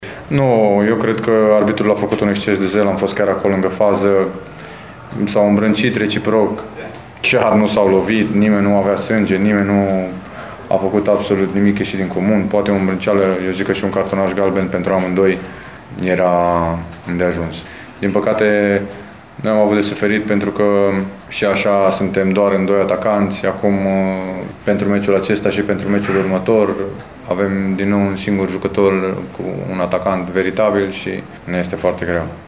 Momentul acesta a fost comentat și la declarațiile de după meci de unul dintre împricinați